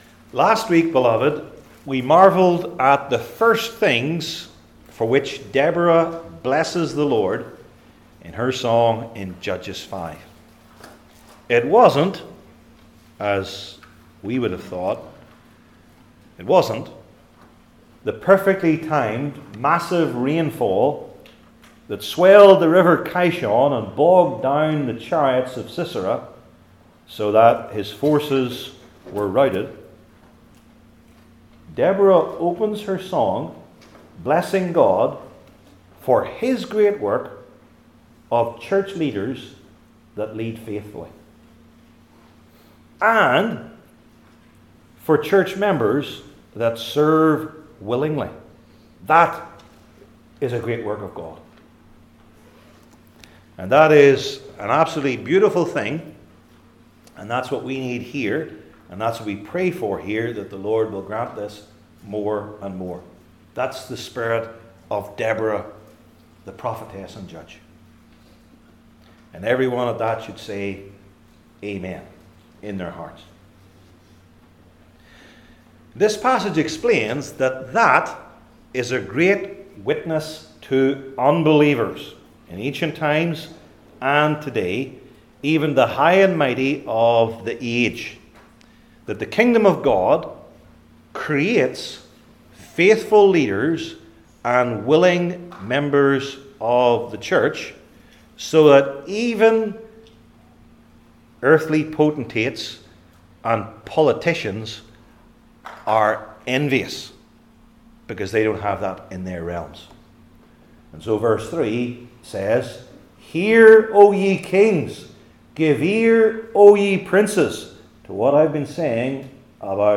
Judges Deborah and Barak Passage: Judges 5:4-5 Service Type: Old Testament Sermon Series I. The Referent II.